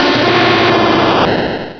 pokeemerald / sound / direct_sound_samples / cries / typhlosion.aif
typhlosion.aif